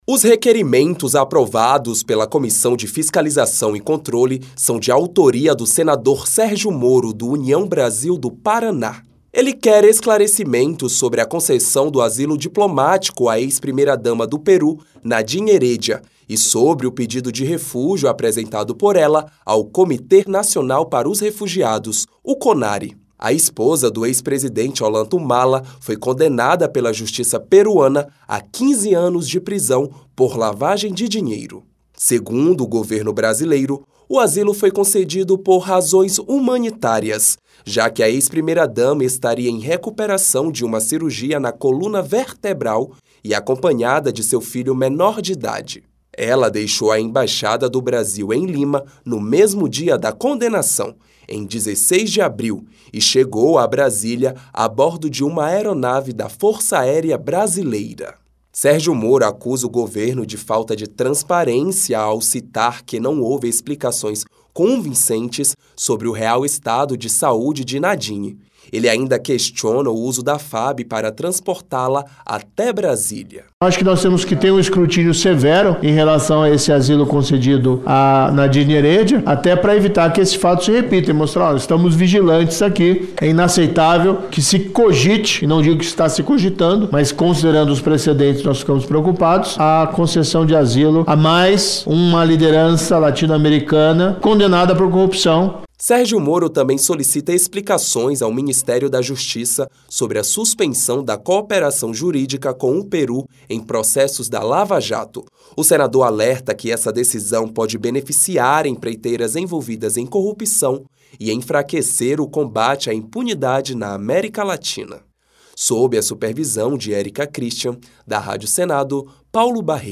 Senador Sergio Moro